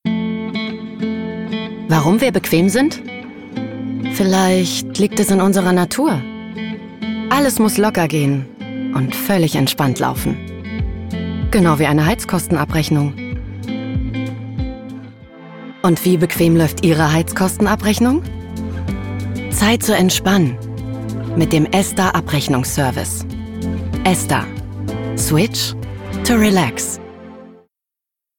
sehr variabel, dunkel, sonor, souverän
Mittel minus (25-45)
Berlinerisch, Norddeutsch, Sächsisch
Werbung Demo (sanft, zielgerichtet)
Commercial (Werbung)